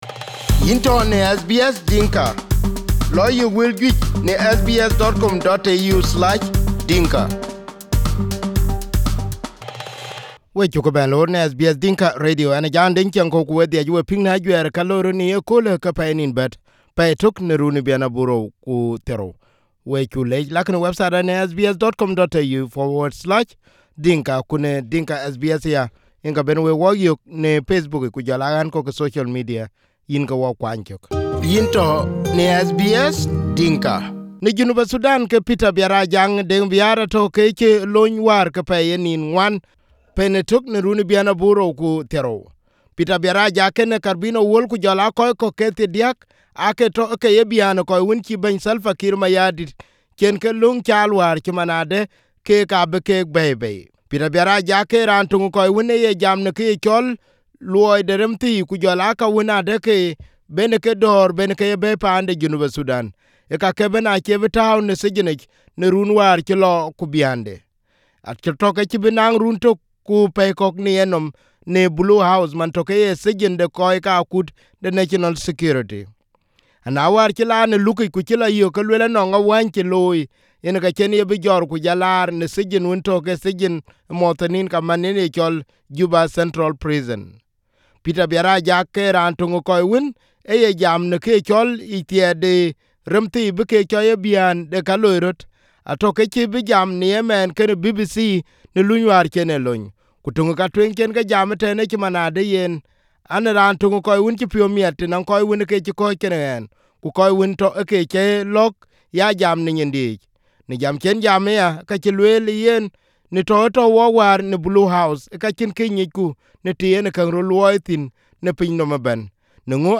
Here is the segment based on the interview by BBC.